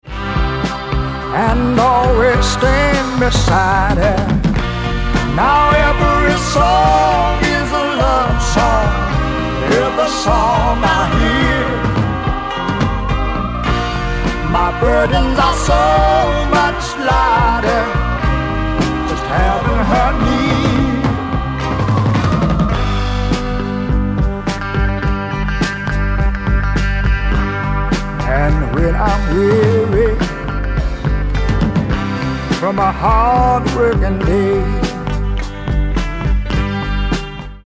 ソリッドなリズム・セクションとリズミカルなホーンをフィーチャーしたスワンプ・ロック傑作
ブルー・アイド・ソウル感溢れるふたりのヴォーカルのコンビネーションも最高の一枚！